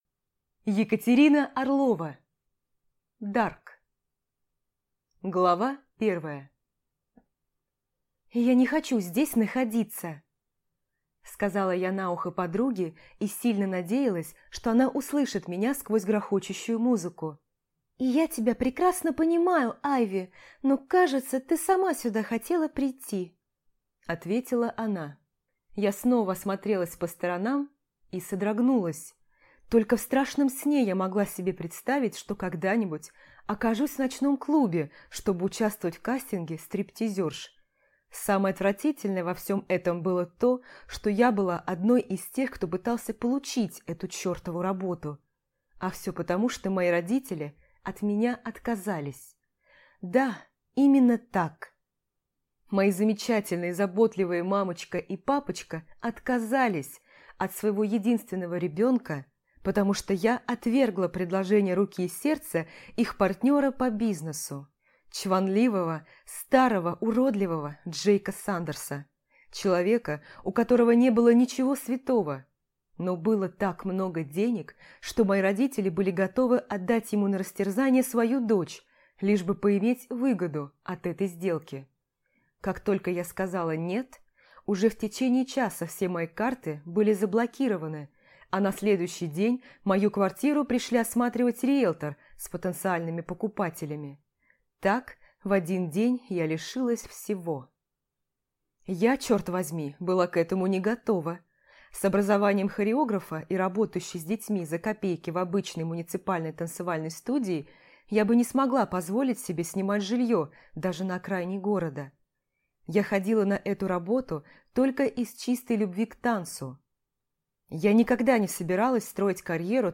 Аудиокнига Дарк | Библиотека аудиокниг